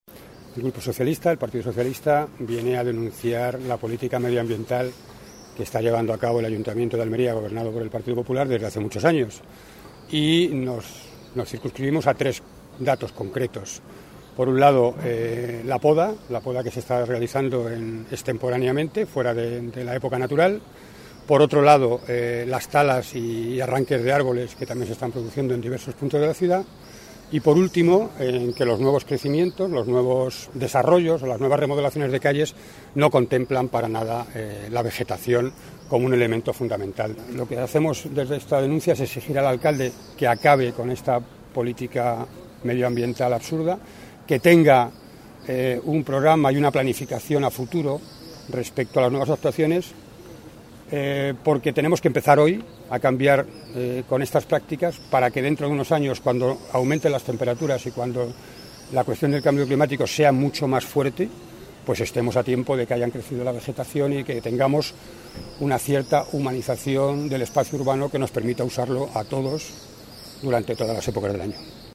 donde ha tenido lugar la rueda de prensa
Eusebio Villanueva, concejal del PSOE en el Ayuntamiento de Almería